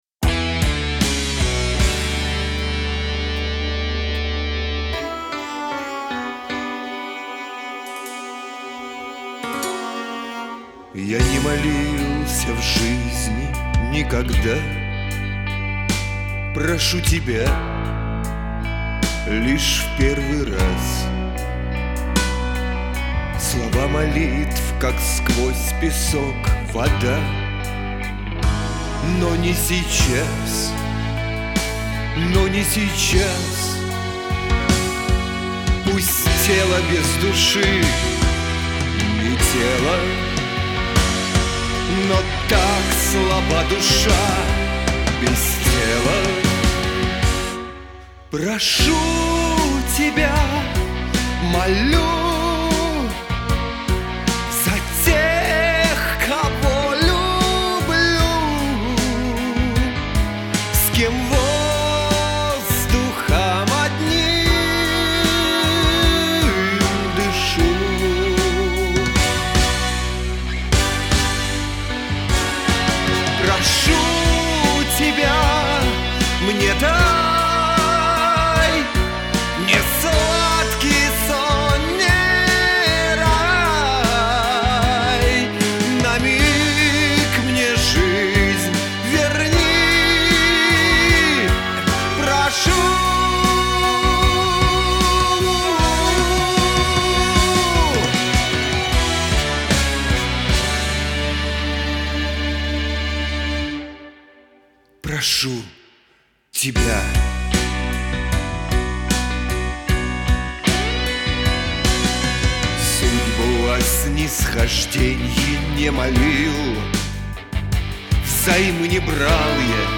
То ли не попал, то ли переиграл.